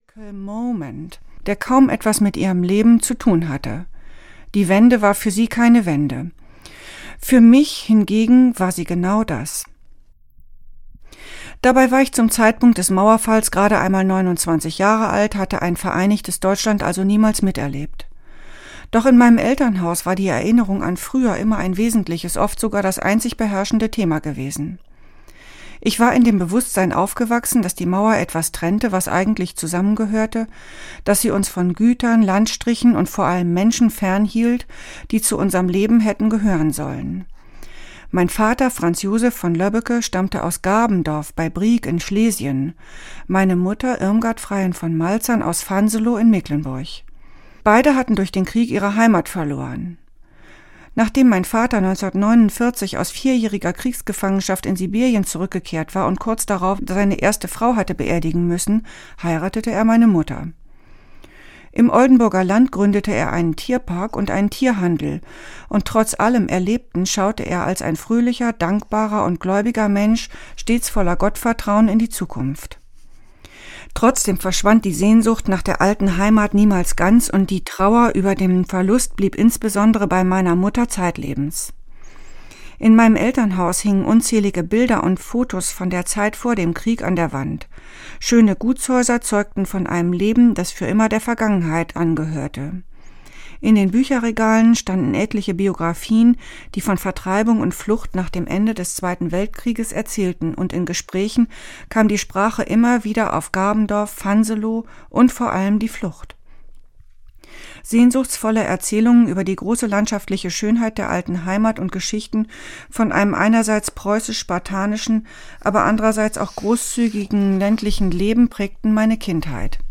Die Apfelgräfin - Daisy Gräfin von Arnim - Hörbuch